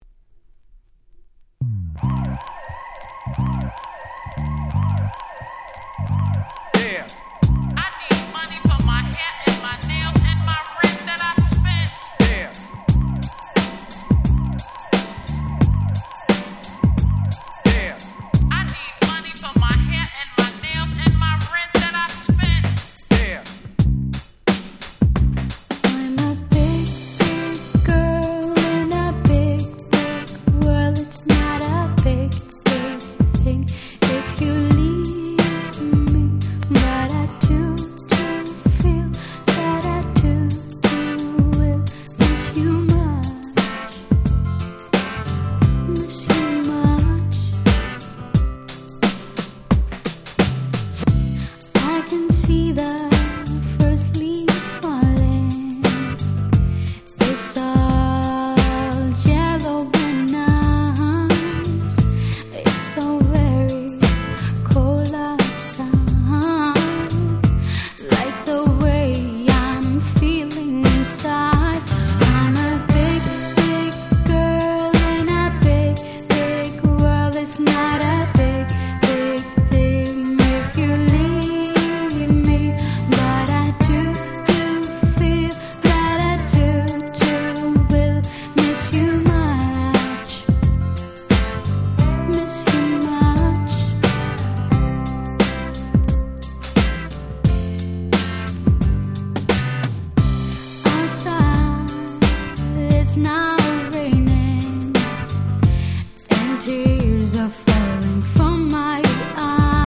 98' Nice R&B !!
ポップでキャッチーな可愛い1曲♪
キャッチー系